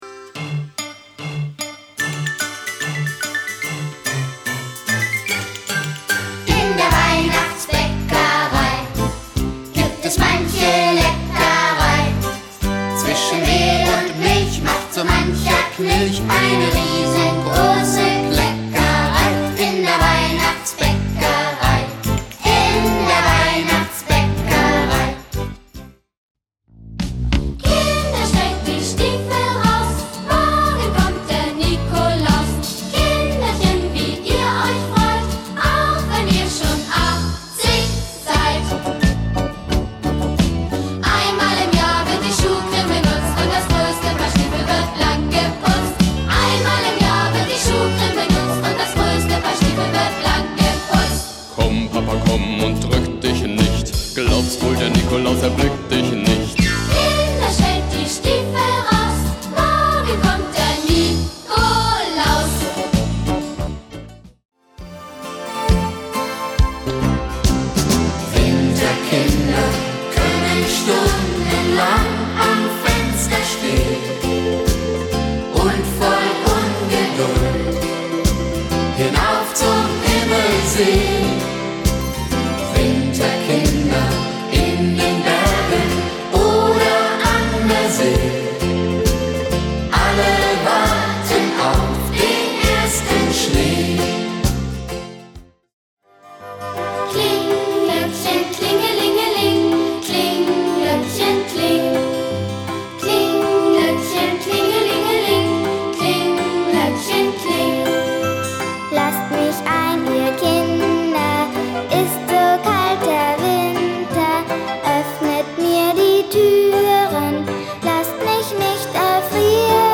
Musik